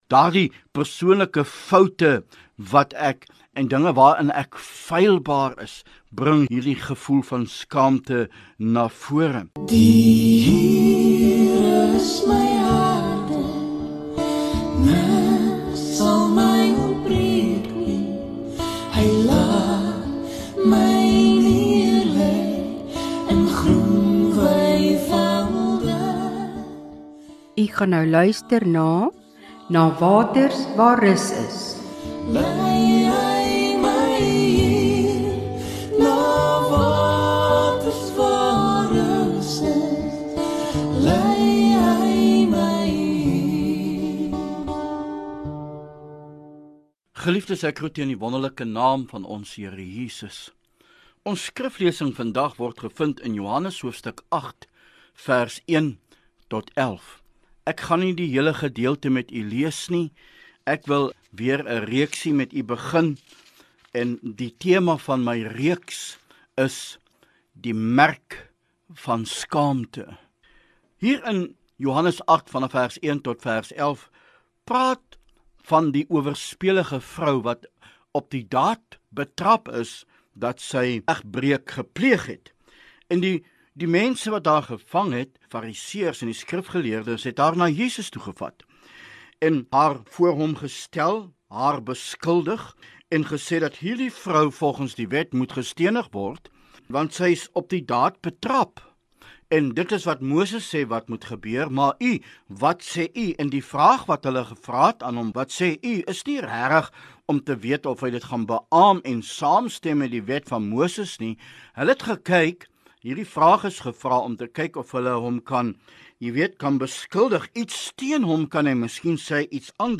DIE PLEK VAN SKAAMTE DEEL 1 DIE PREDIKER DEEL MET SY GEHOOR MBT TOT DIE DINGE WAT GEDOEN IS IN DIE LEWE WAAROOR MENSE NIE NET SKAAMTE ERVAAR NIE, MAAR OOK 'N VALSE SKAAMTE WAT BELEEF WORD WANNEER DAAR VERGIFNIS GEVRA EN ONTVANG IS.